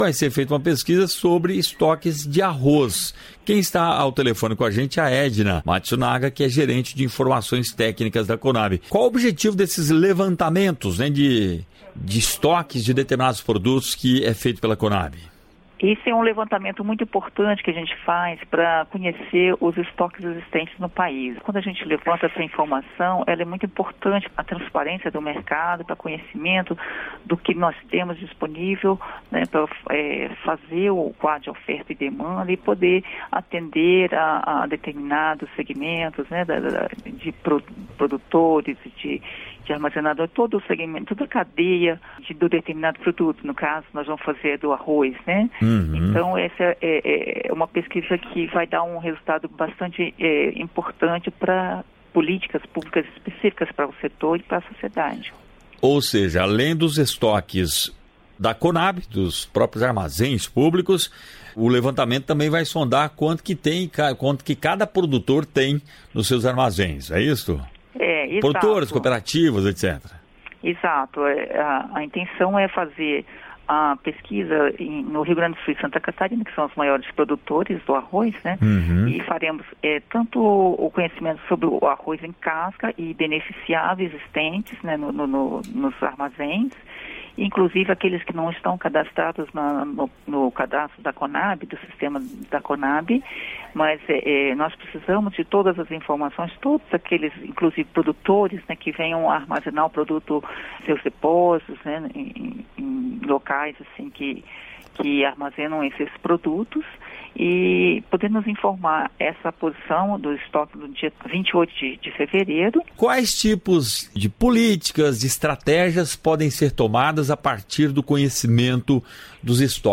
Entrevista: Saiba mais sobre a pesquisa da Conab sobre o arroz